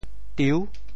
酎 部首拼音 部首 酉 总笔划 10 部外笔划 3 普通话 zhòu 潮州发音 潮州 diu6 文 中文解释 酎 <名> 经过两次以至多次复酿的醇酒 [double-fermented wine] 酎,三重醇酒也。
tiu6.mp3